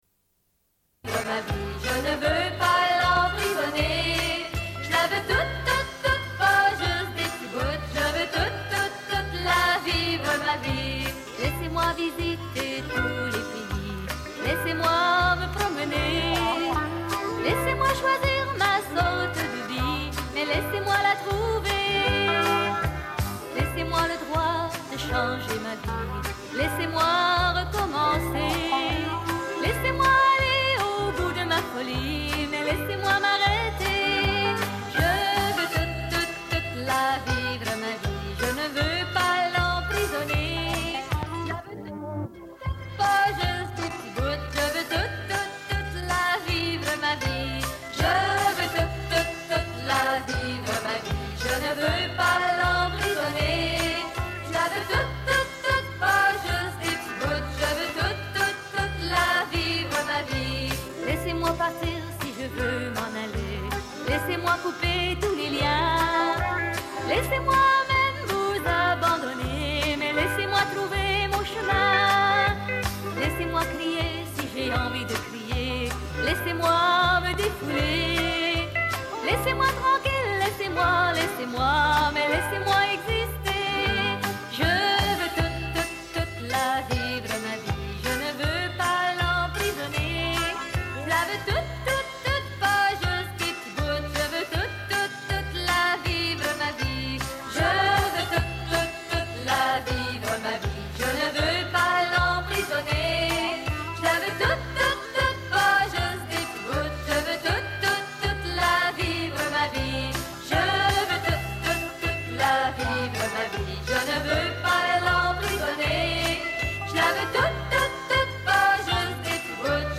Une cassette audio, face B28:35